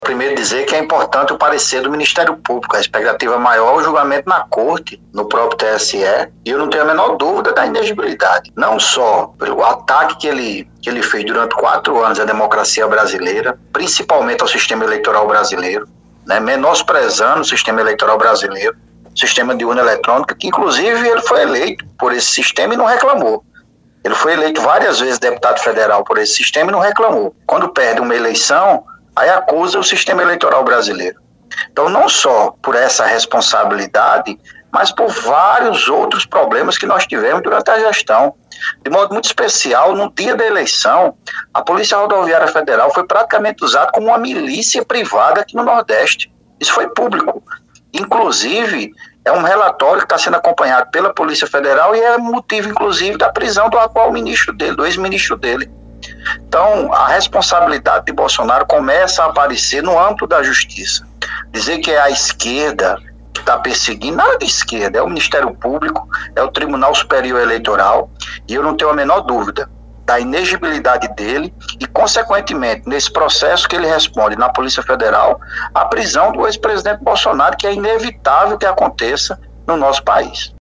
Os comentários do dirigente petista foram registrados pelo programa Correio Debate, da 98 FM, de João Pessoa, nesta quinta-feira (13/04).